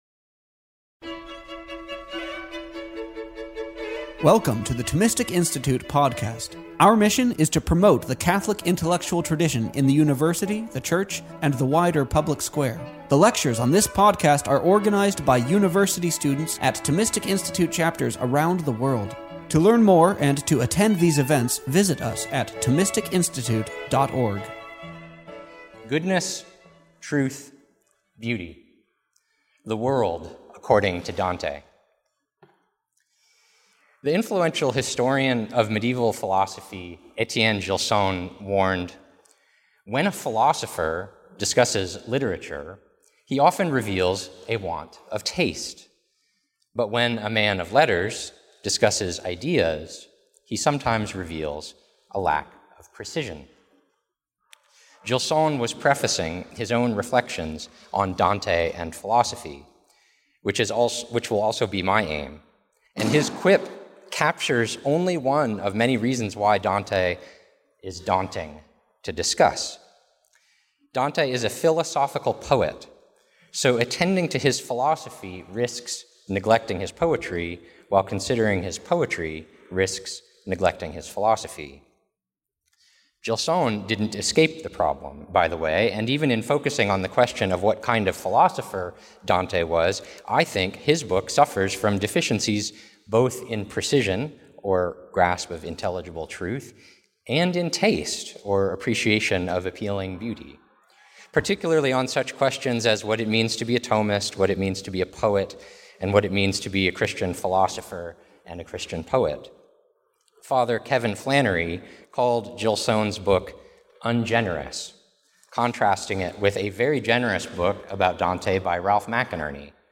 This week, we are reposting some of our favorite talks related to Christmas and the Incarnation of our Lord. This talk was delivered on March 30, 2021, at North Carolina State University.